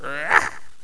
carpet_attack2.wav